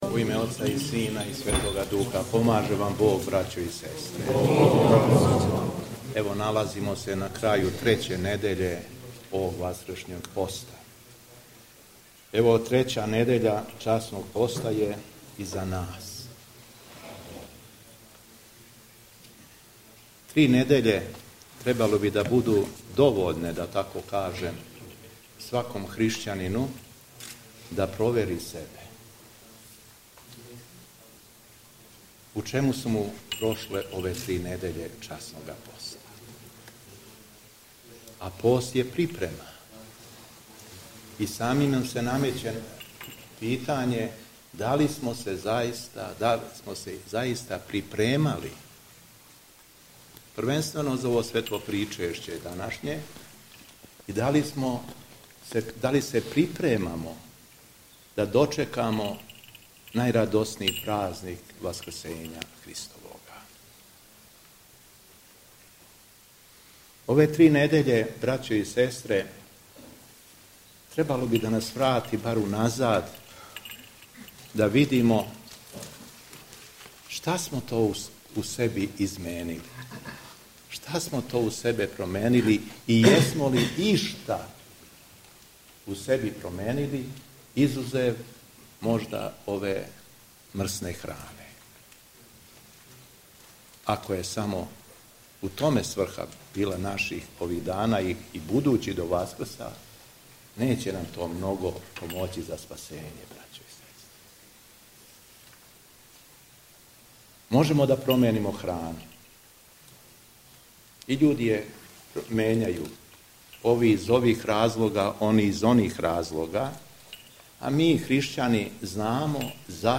У петак Треће седмице Васкршњег поста, 5. априла 2024. године, Његово Преосвештенство Епископ шумадијски Господин Јован служио је Свету архијерејску Литургију пређеосвећених дарова у Цркви Светог пророка Јеремије у Милошеву код Јагодине.
Беседа Његовог Преосвештенства Епископа шумадијског г. Јована